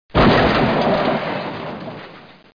00214_Sound_Bash.mp3